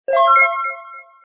success2.mp3